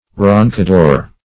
roncador - definition of roncador - synonyms, pronunciation, spelling from Free Dictionary
Roncador \Ron`ca*dor"\ (r[o^][ng]`k[.a]*d[=o]r"), n. [Sp., a